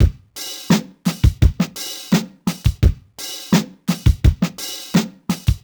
Index of /musicradar/sampled-funk-soul-samples/85bpm/Beats
SSF_DrumsProc1_85-02.wav